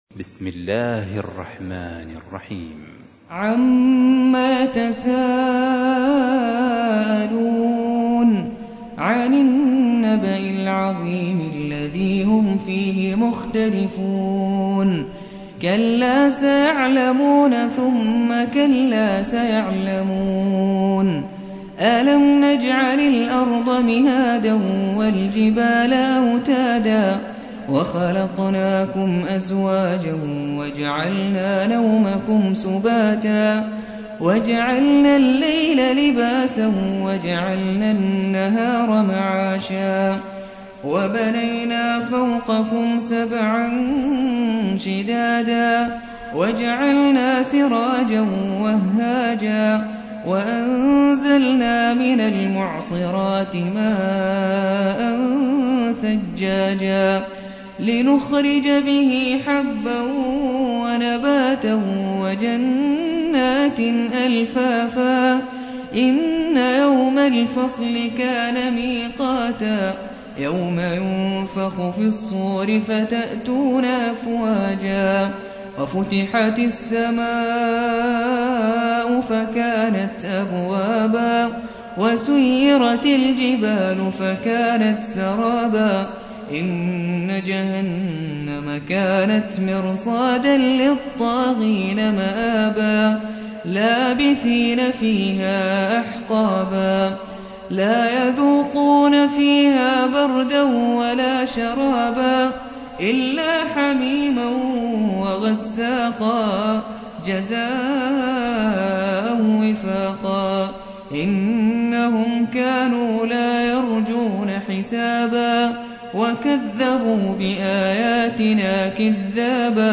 78. سورة النبأ / القارئ